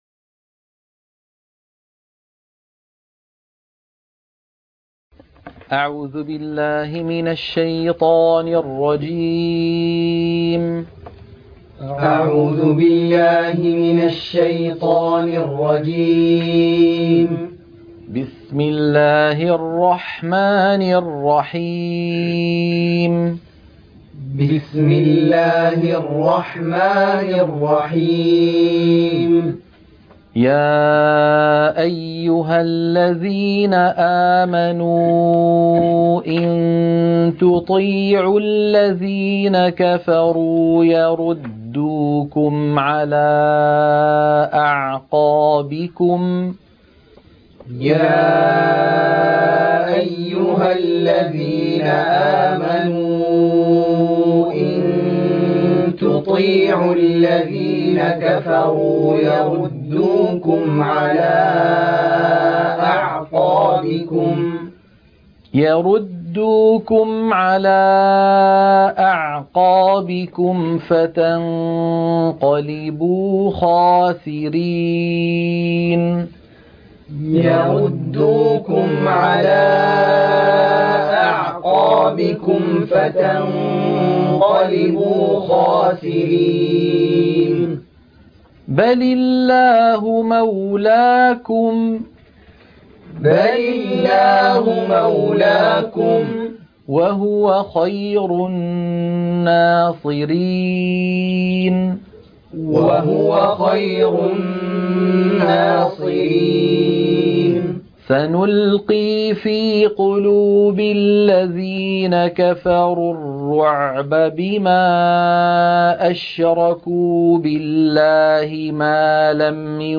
تلقين سورة آل عمران - الصفحة 69 التلاوة المنهجية - الشيخ أيمن سويد